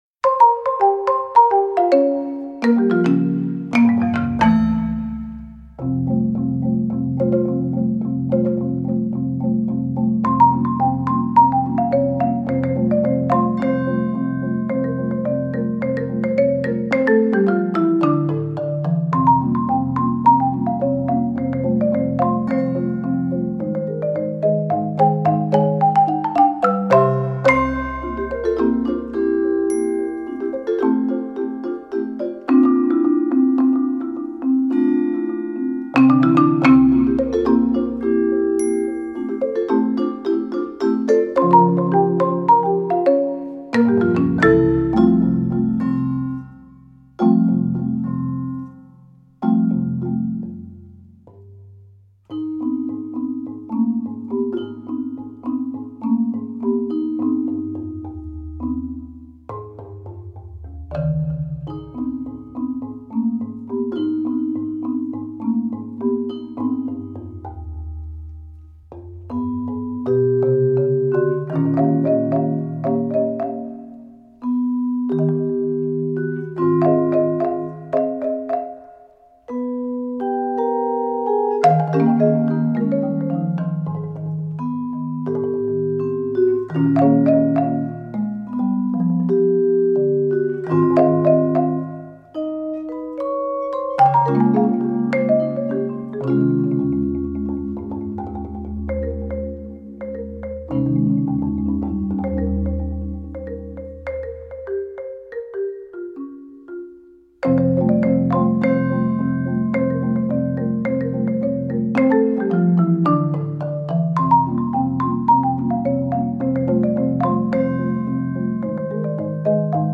Voicing: Mallet Sextet